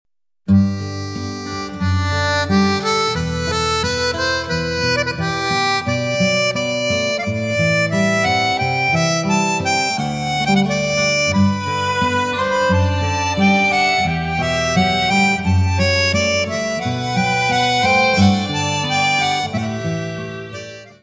: stereo; 12 cm
Music Category/Genre:  World and Traditional Music